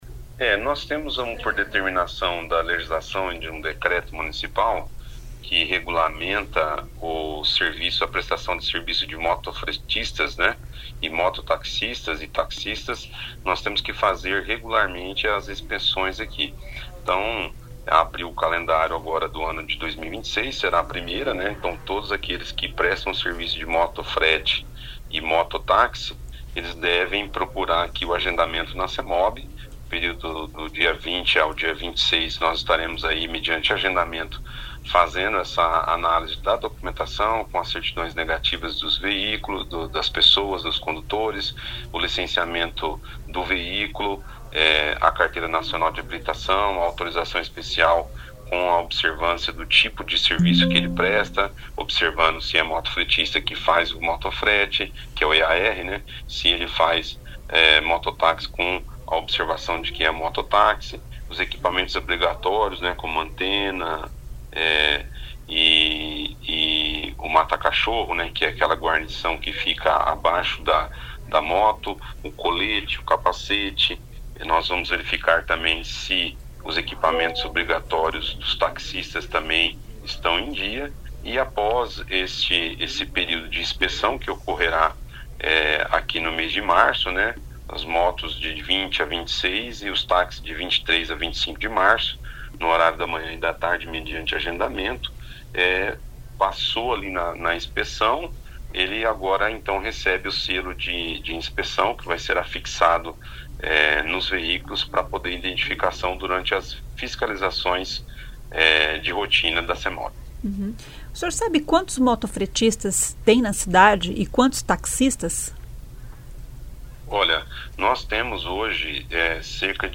A Secretaria Municipal de Mobilidade Urbana de Maringá (Semob) está convocando os motofretistas e os taxistas para a 1ª Vistoria Veicular de 2026, que será realizada na sede da própria secretaria, que fica na Avenida Colombo, nº 3.114. O secretário Luciano Brito explica qual o período de inspeção e os documentos necessários.